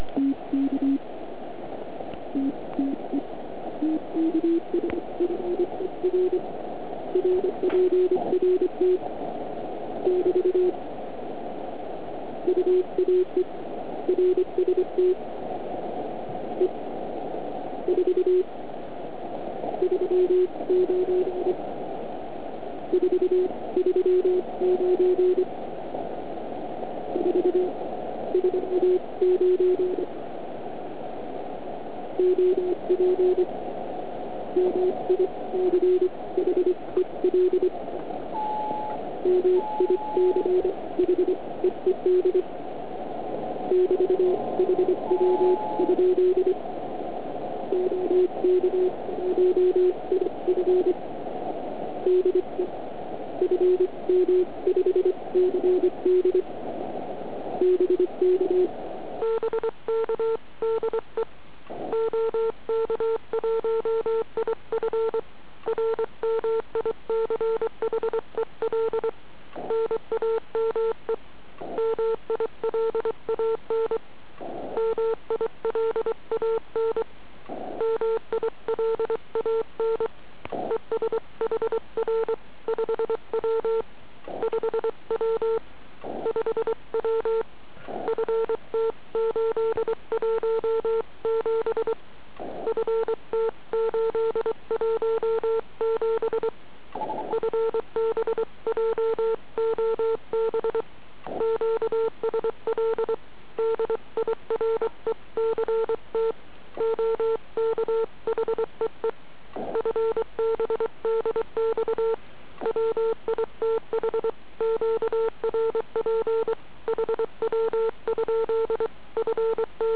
Slyšitelnost byla vynikající.